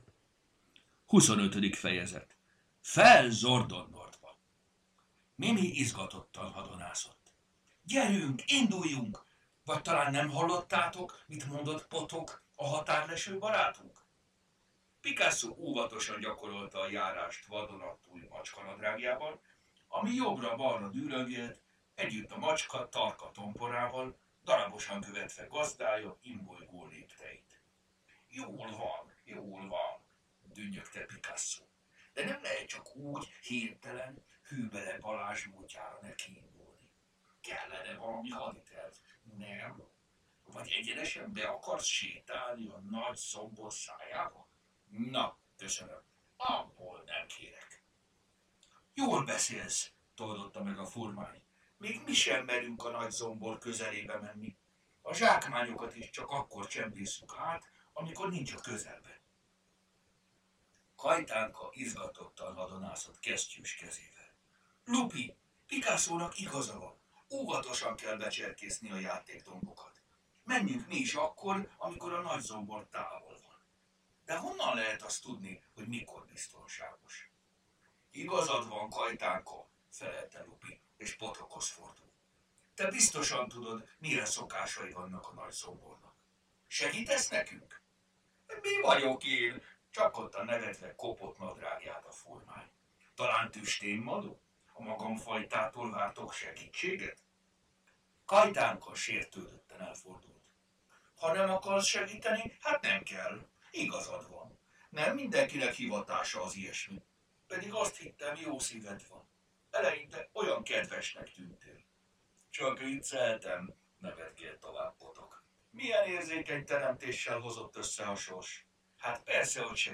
Hangos mese: Fel Zordonnordba!